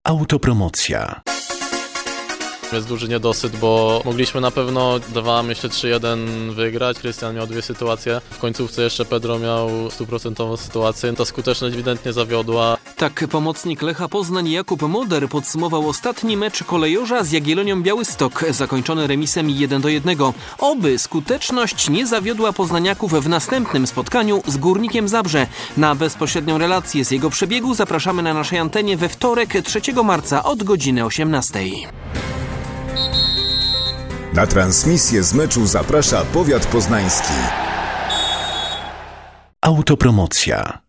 Ale też nasze klipy zapowiadające mecze Lecha są przygotowane z dużym poczuciem humory, wykorzystując gwarę czy też różnego rodzaju motywy stadionowe.